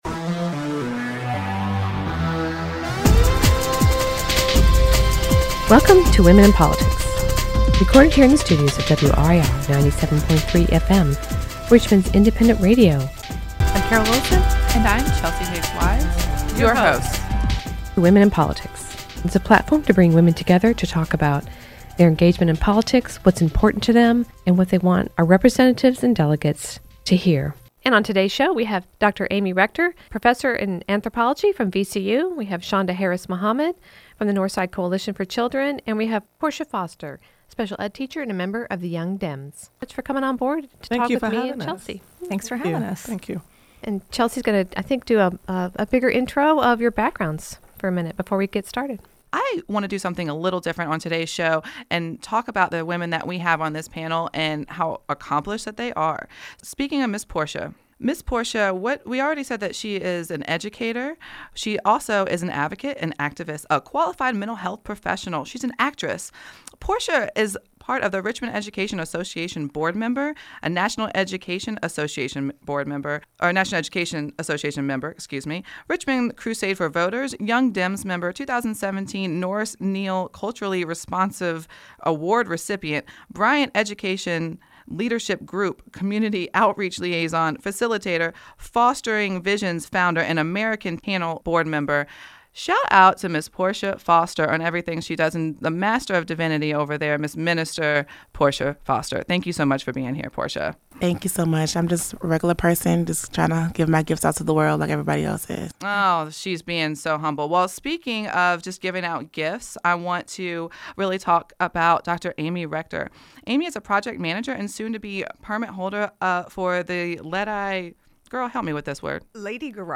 Women and Politics: A discussion with Educators – Part I